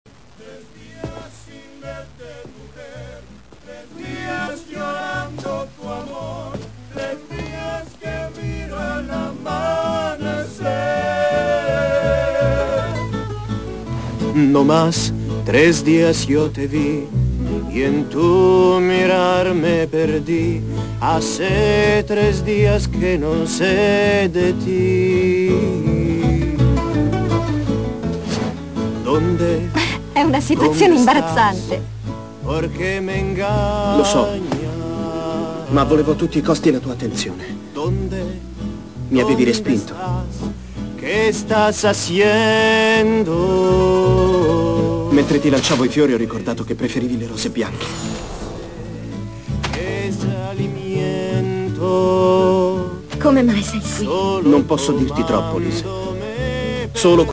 Ascolta un po' in versione italiana...